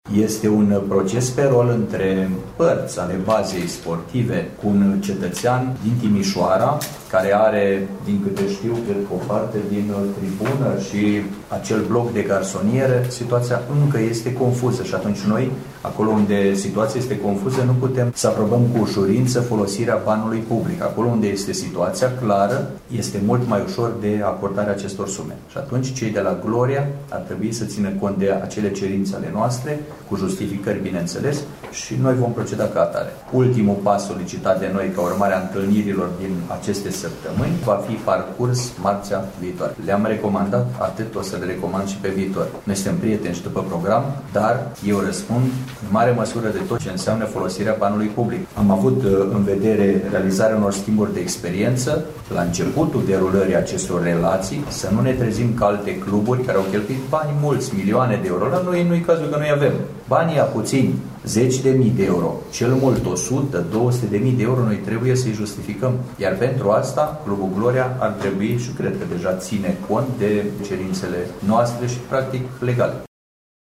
Ascultați declarația primarului Mihai Stepanescu: